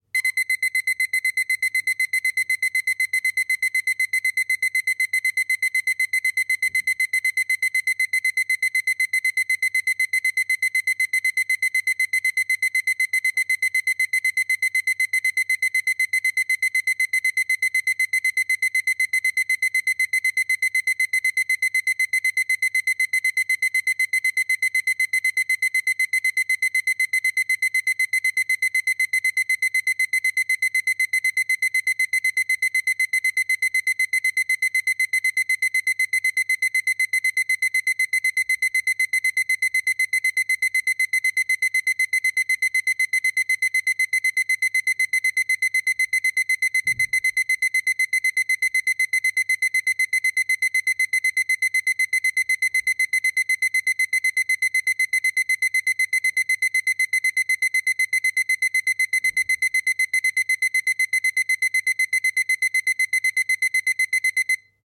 电器报警
描述：电子闹钟响起
标签： 唤醒 唤醒 蜂鸣报警 闹钟 时钟 电动
声道立体声